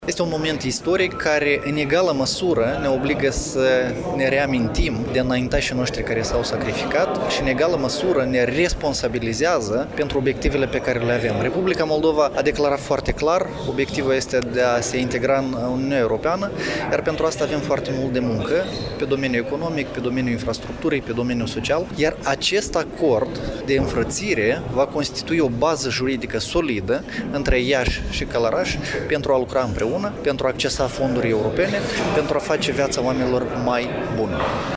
La Iași, a fost semnat, în ședința Consiliului Local de astăzi, protocolul de înfrățire între municipiul Iași și Raionul Călărași, din Republica Moldova.